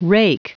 Prononciation du mot rake en anglais (fichier audio)
Prononciation du mot : rake